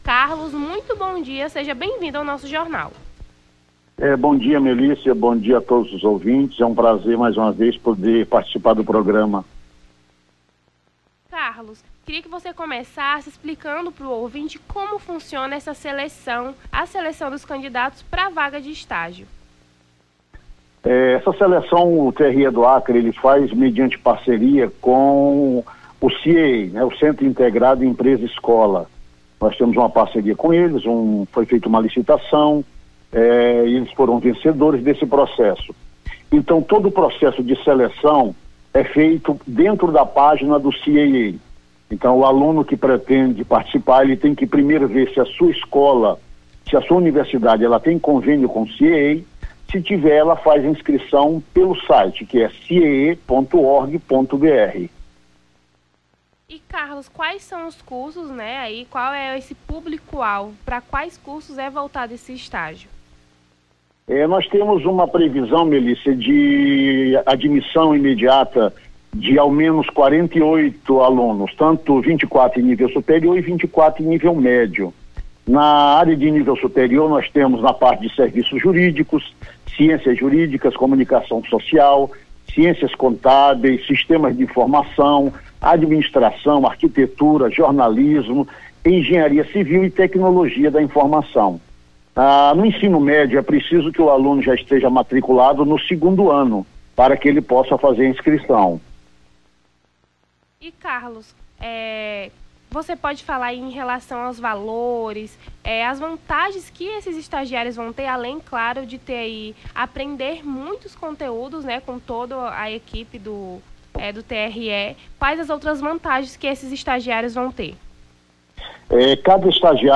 Nome do Artista - CENSURA - ENTREVISTA (PROCESSO SELETIVO DE ESTAGIO) 05-10-23.mp3